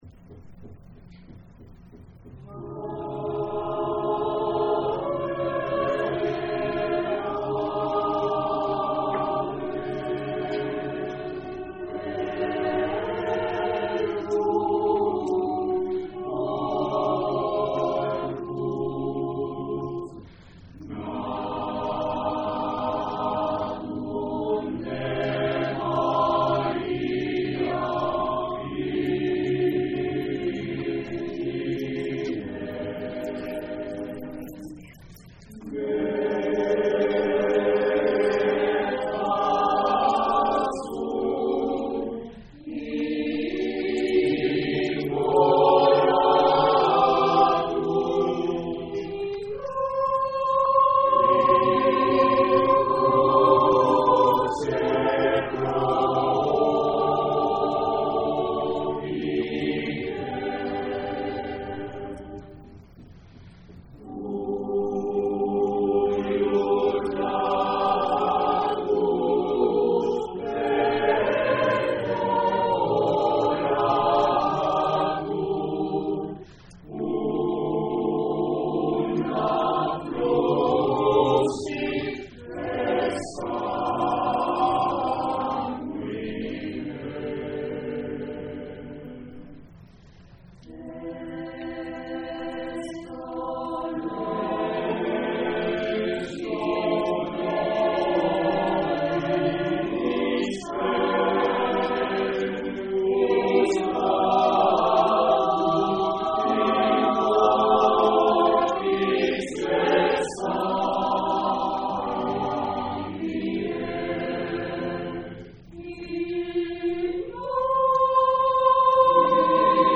Es una  muestra de  canciones de diferentes estilos que hemos interpretado en los Conciertos (las grabaciones son en directo).